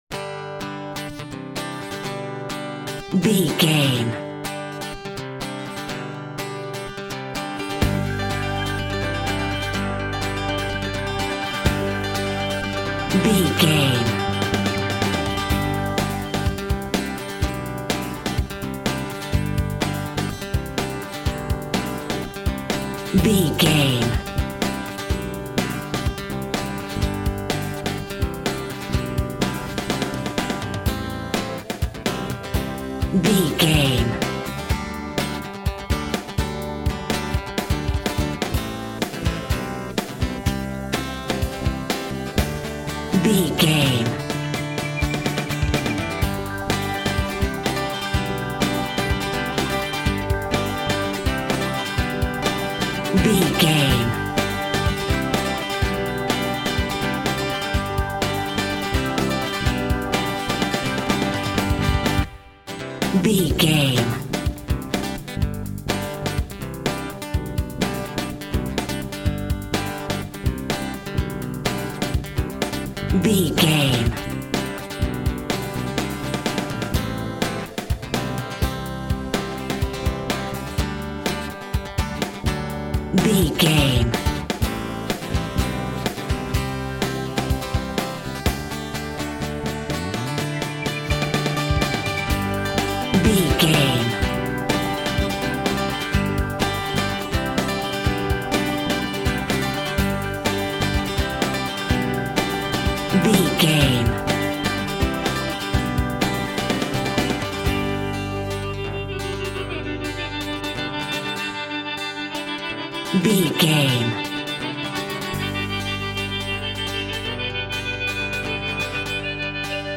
American Boy Band Music Cue.
Ionian/Major
cheesy
Teen pop
electro pop
pop rock
drums
bass guitar
electric guitar
piano
hammond organ